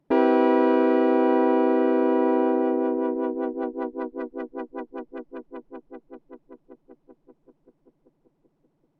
LFO合成器和弦
描述：在JU06合成器上创建。
标签： 89 bpm Electro Loops Synth Loops 1.52 MB wav Key : Unknown Ableton Live
声道立体声